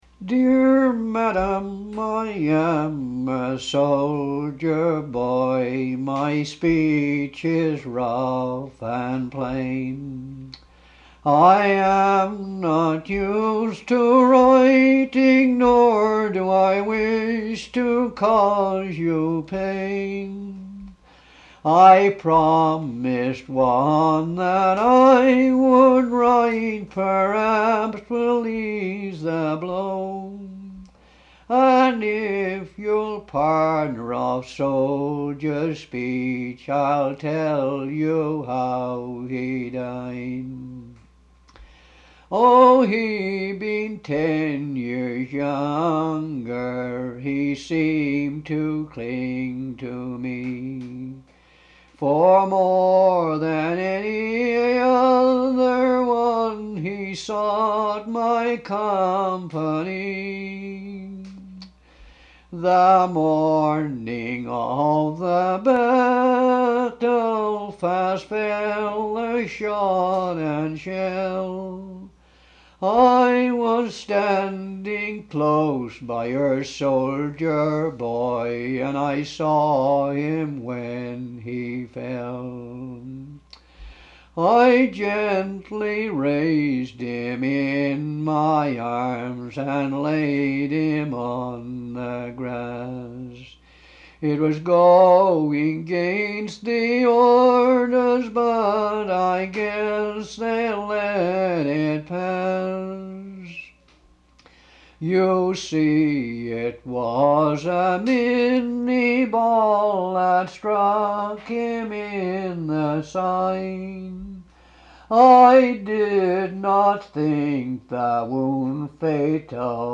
Town: Markhamville, NB